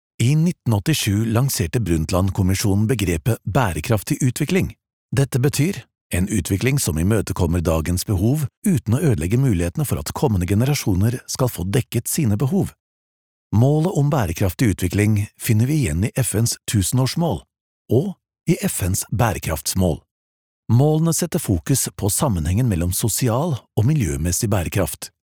Tief, Vielseitig, Zuverlässig, Erwachsene, Warm
Unternehmensvideo